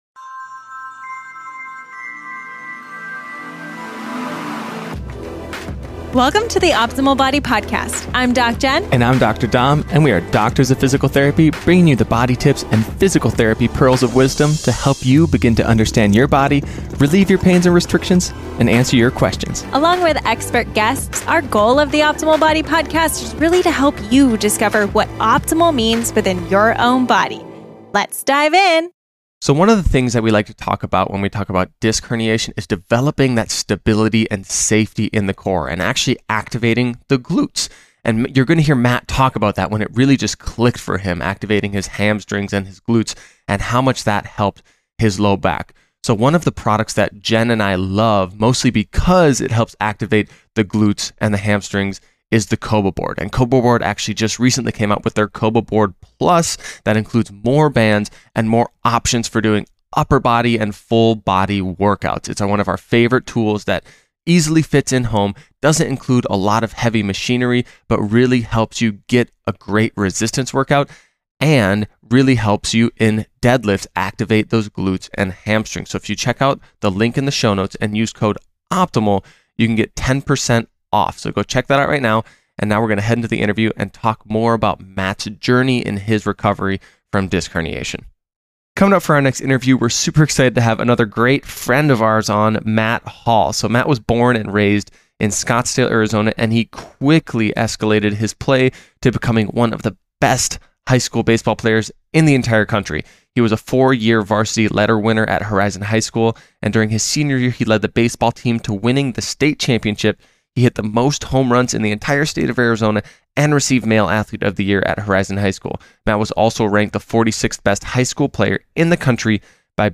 What you will learn in this interview with :